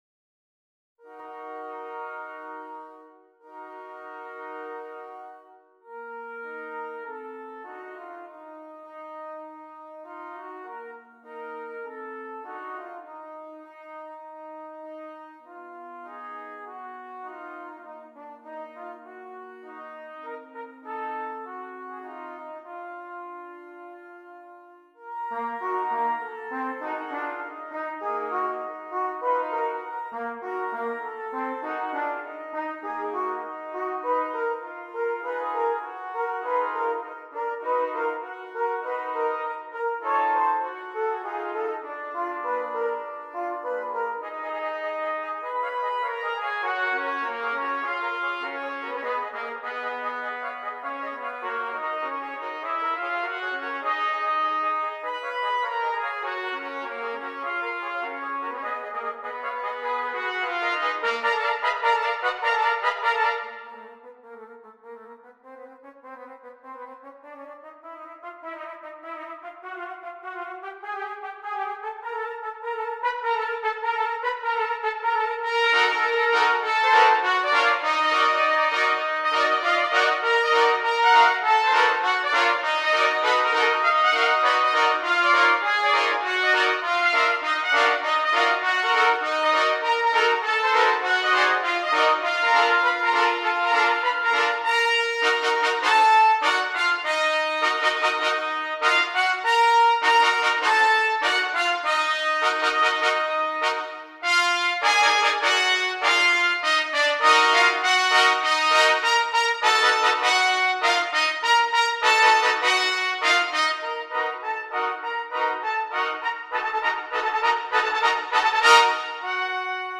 8 Trumpets
riveting tone poem
scored for eight trumpets
including flugelhorn and lead player